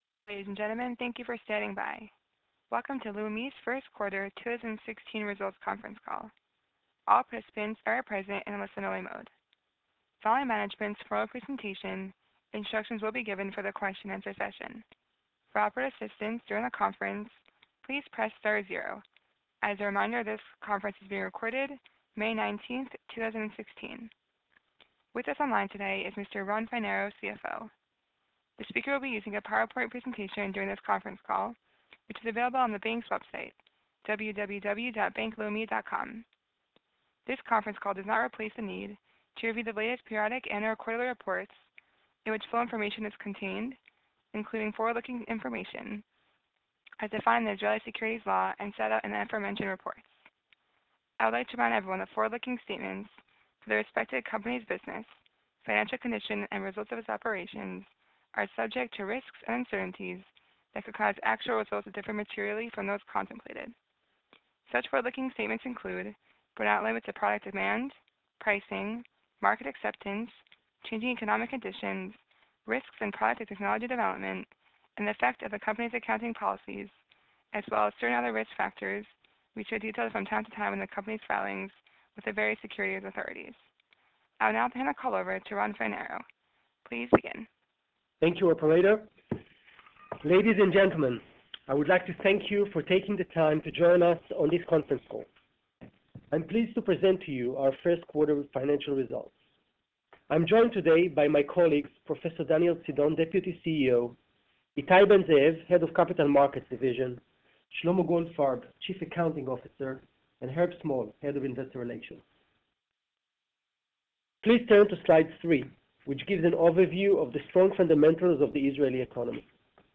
Investor Presentation and Conference Call